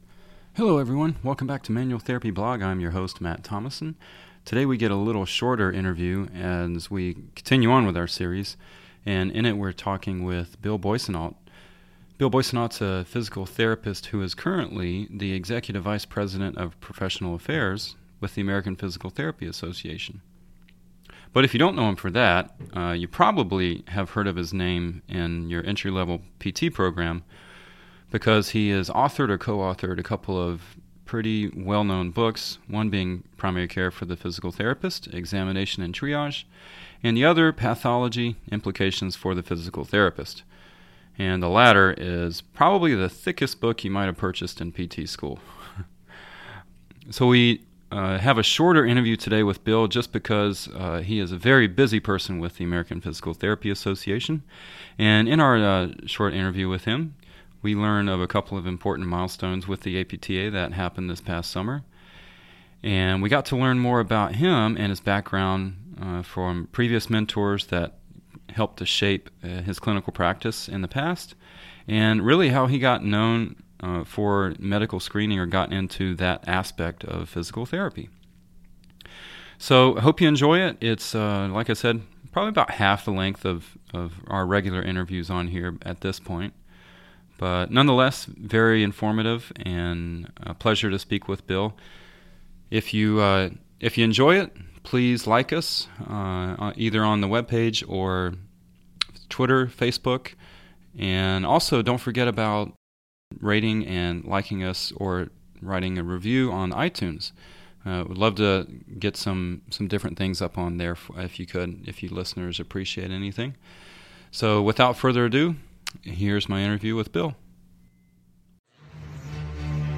While this conversation is much shorter than our usual, it is not lacking in quality!